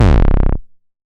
MoogAgressPulseD.WAV